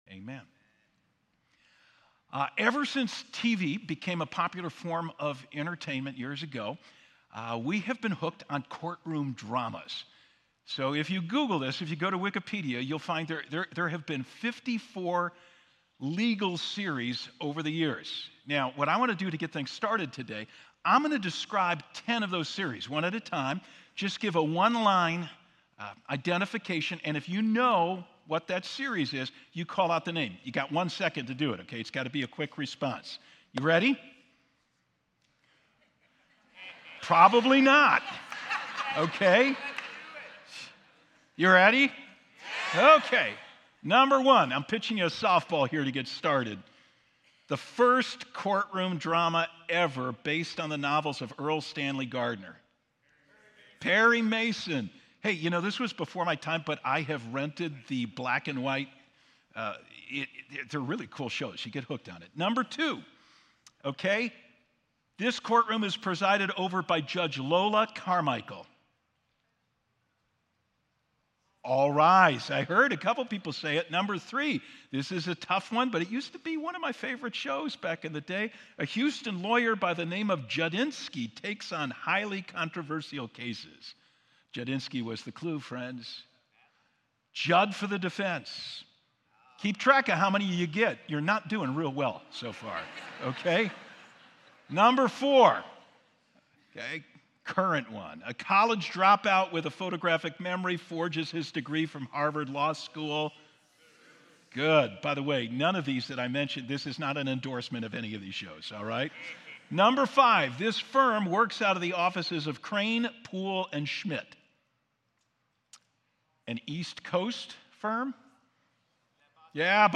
10-6-24-Sermon.mp3